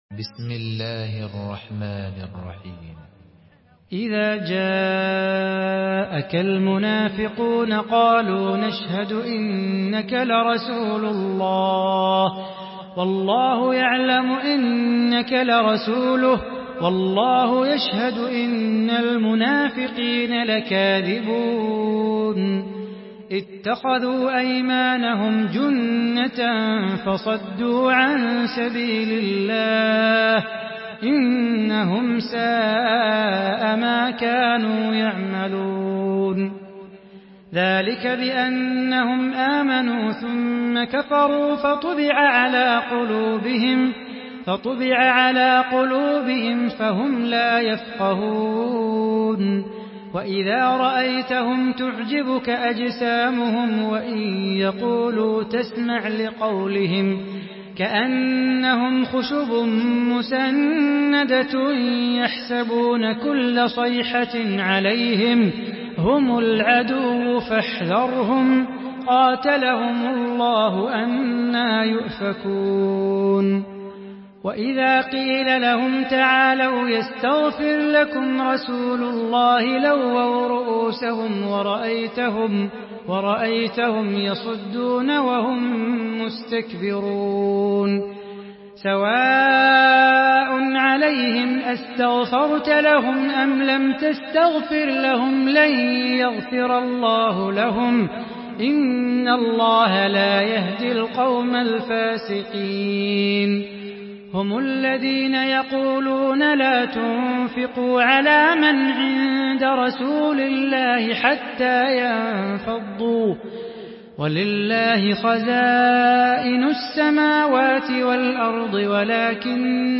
Surah Münafikun MP3 in the Voice of Salah Bukhatir in Hafs Narration
Murattal